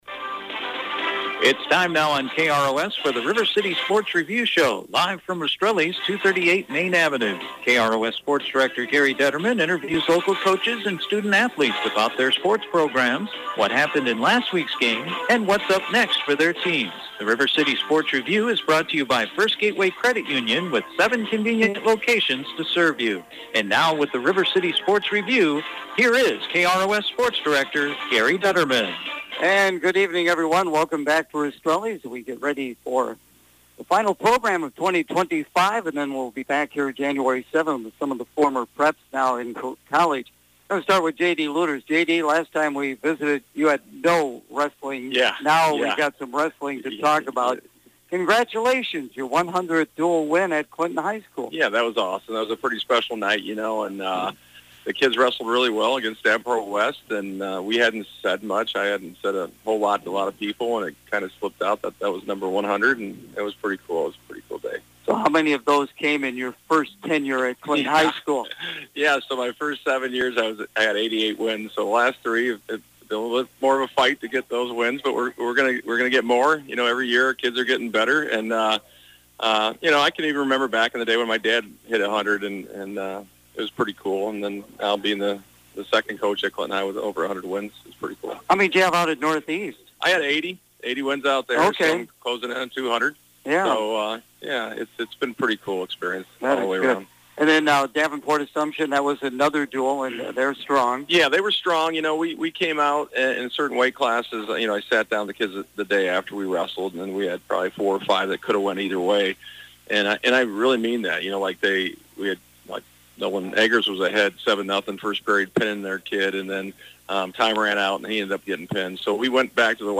The River City Sports Review Show on Wednesday night from Rastrelli’s Restaurant